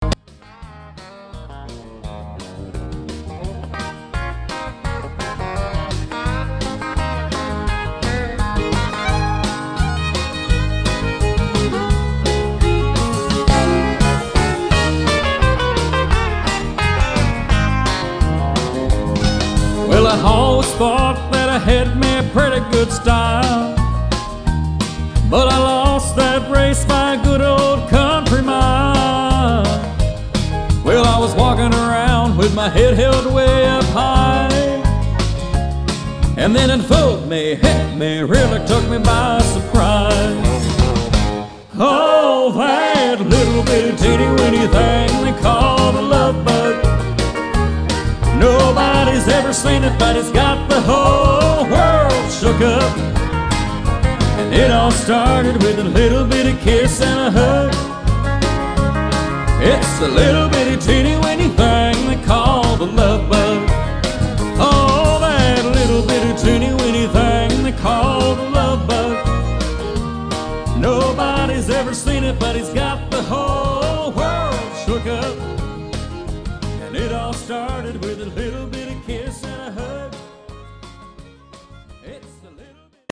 11 Country Songs